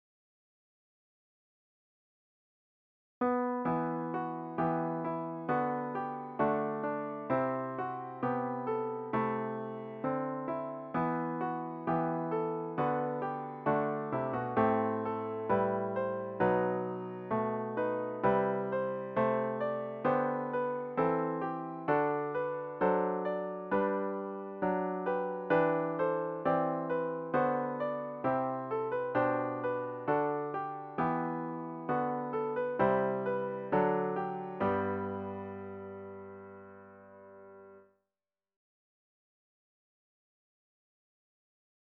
The hymn should be performed at a tranquil♩= ca. 66.